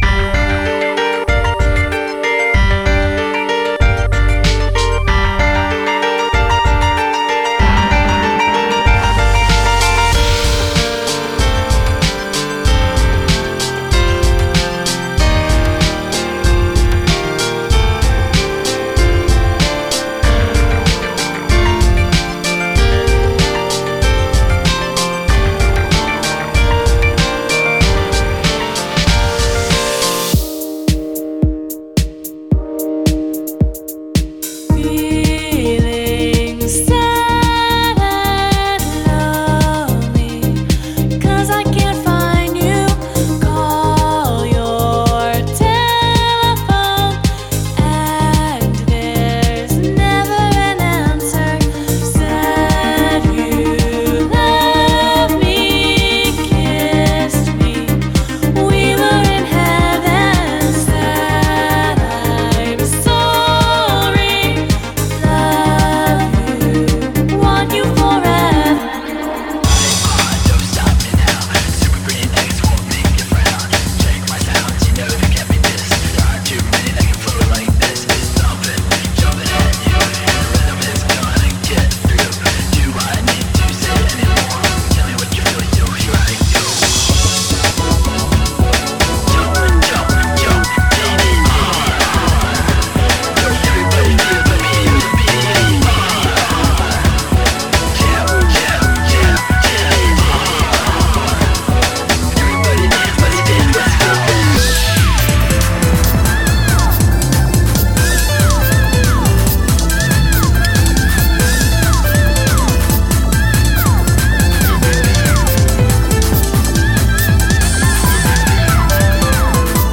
BPM45-145